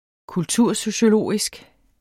Udtale [ kulˈtuɐ̯ˀsoɕoˌloˀisg ]